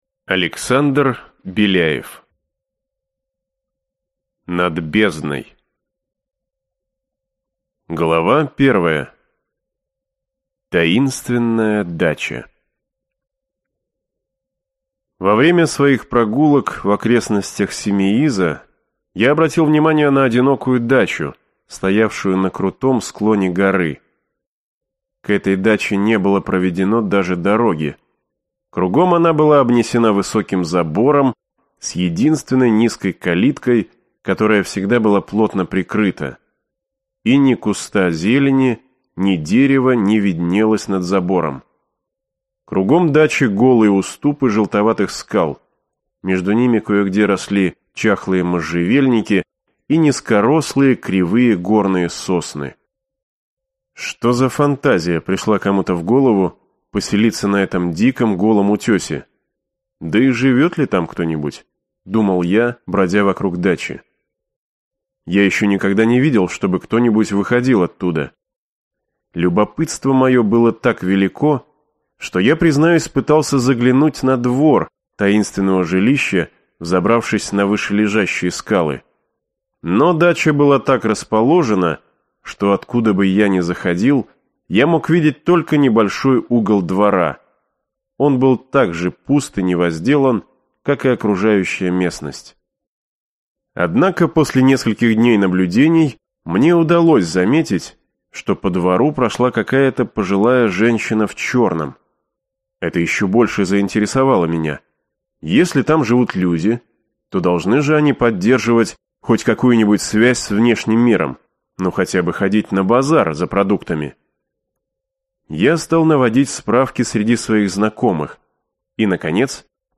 Аудиокнига Замок ведьм. Мертвая голова. Над бездной | Библиотека аудиокниг